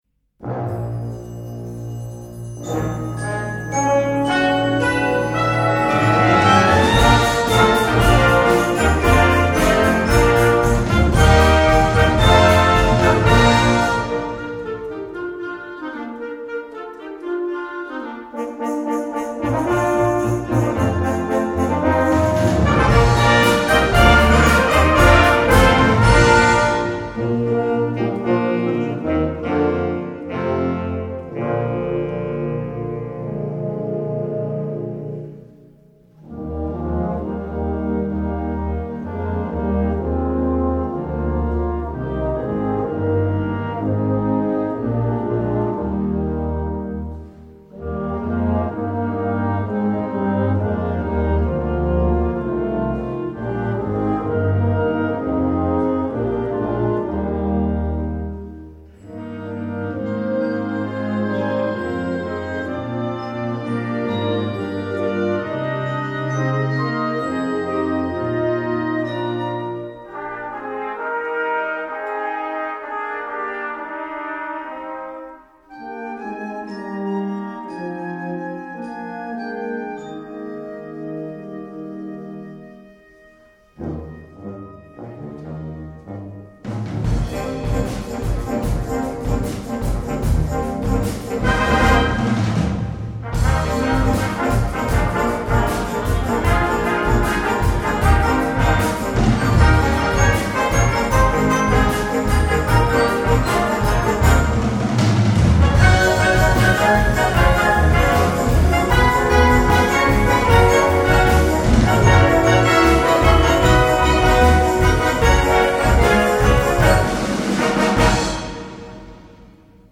Komponist: Traditionell
Gattung: Weihnachts-Medley
Besetzung: Blasorchester